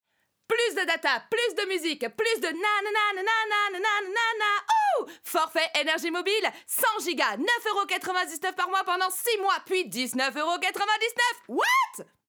Voix off
20 - 45 ans - Mezzo-soprano